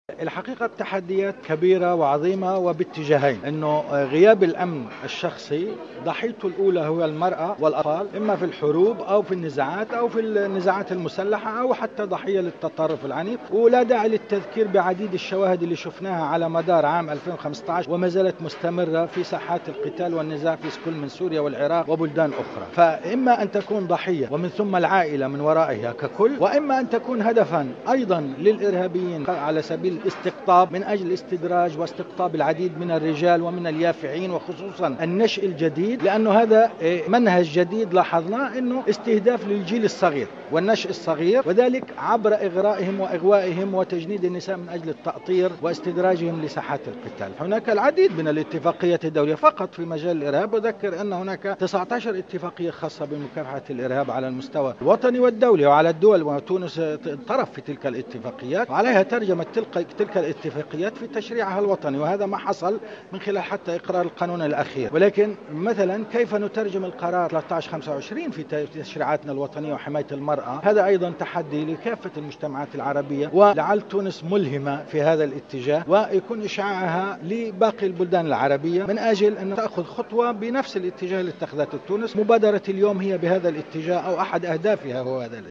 تصريح لمراسل الجوهرة "اف ام" اليوم الأربعاء على هامش أشغال مؤتمر دولي